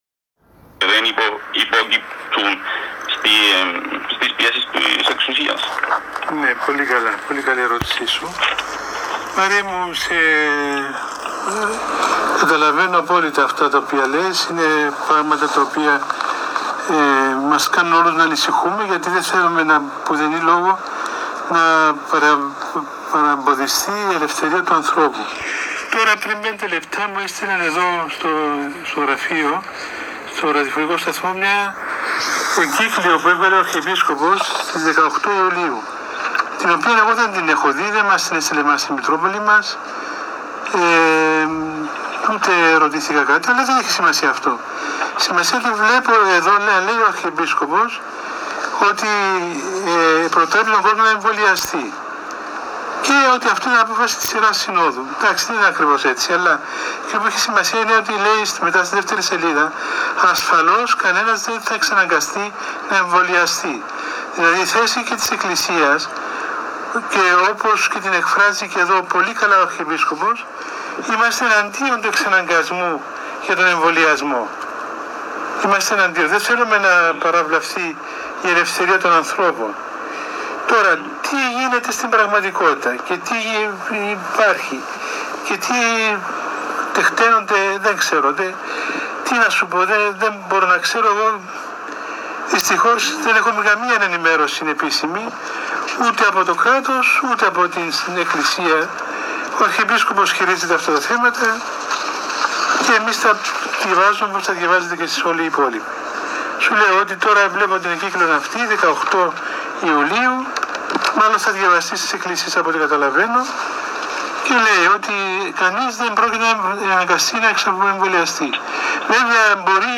Σε ερώτηση ακροατή σχετικά με τα εμβόλια, την υποχρεωτικότητα και τον διχασμό κλήθηκε να απαντήσει ο Πανιερ. Μητροπολίτης Λεμεσού κ. Αθανάσιος στην εκπομπή “Επερώτησον τον πατέρα σου” του ραδιοφωνικού σταθμού της Ιεράς Μητροπόλεως το Σάββατο 17 Ιουλίου 2021.